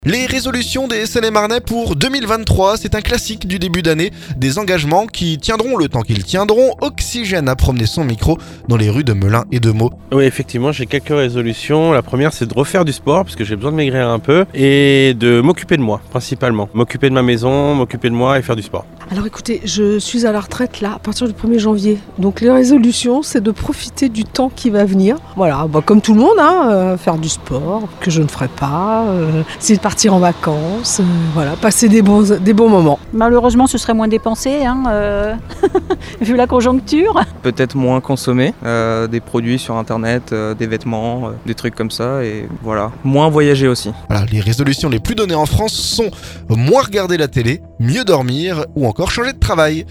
Oxygène a promené son micro dans les rues de Melun et Meaux.